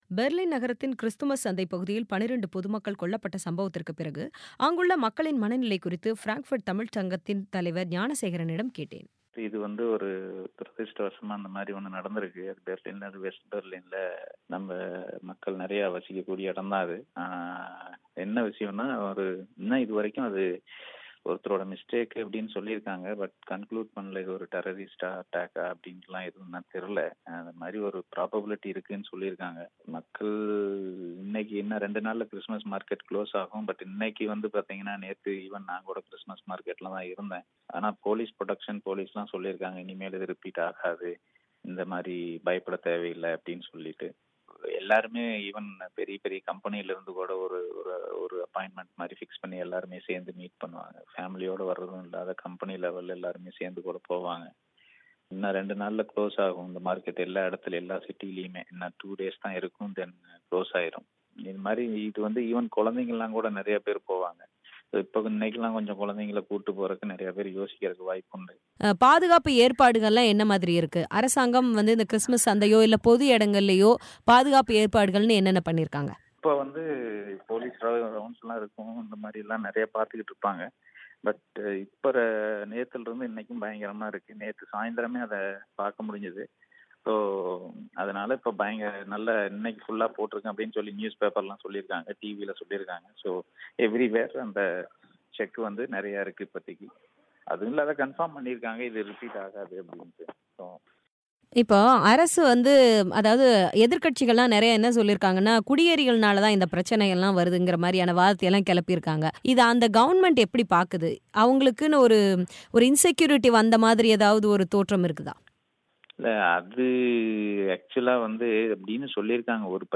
ஜெர்மனியின் கிறிஸ்துமஸ் சந்தையில் நிகழந்த தாக்குதலுக்கு பிறகு அங்குள்ள மக்களின் மனநிலையை விவரிக்கும் ஒரு தமிழரின் பேட்டி